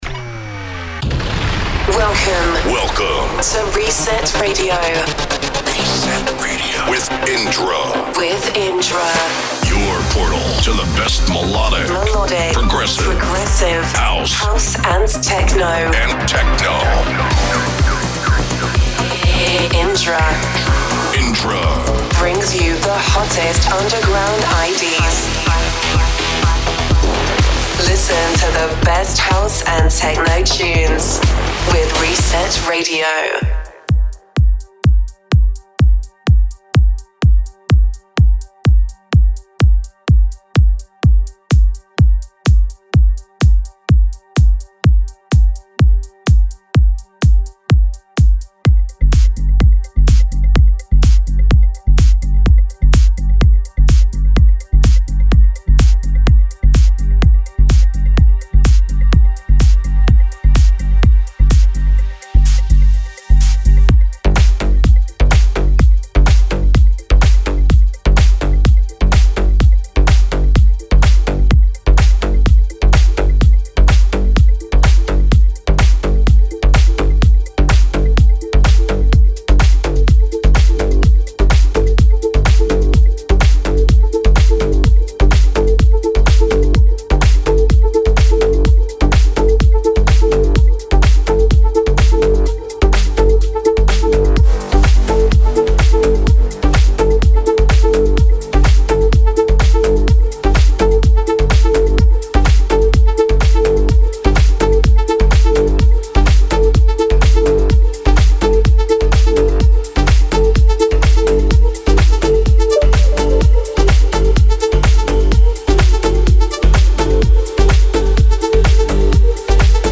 30 Min Mix